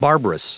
Prononciation du mot barbarous en anglais (fichier audio)
Prononciation du mot : barbarous